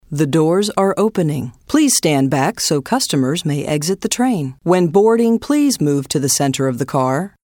As one who works in the voice over business, I love the way she sounds, despite some rough edges in her delivery.